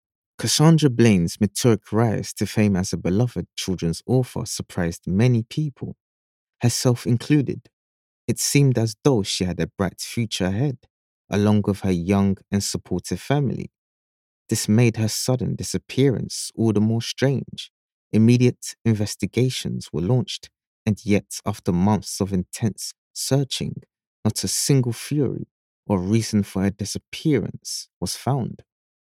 E-learning Voice Over Narration Artists & Actors
English (Caribbean)
Adult (30-50) | Yng Adult (18-29)